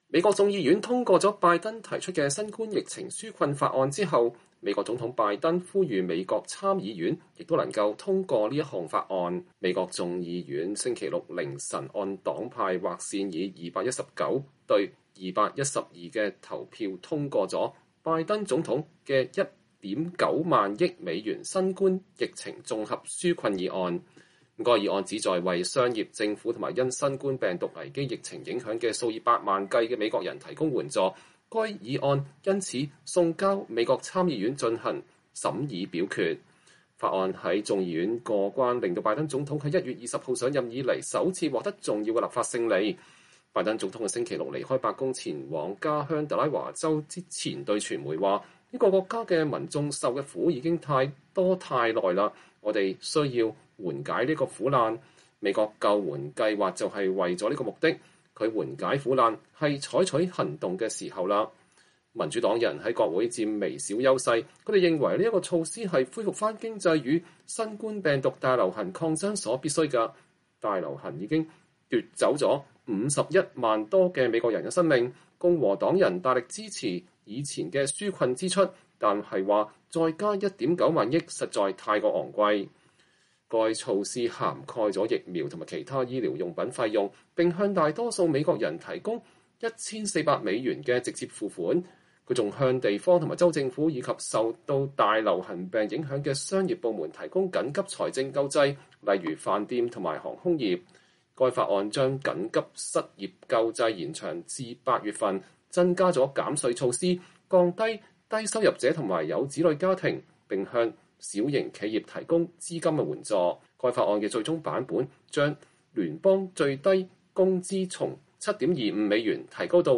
美國總統拜登2月27日在白宮呼籲美國參議院通過他提出的新冠疫情紓困議案。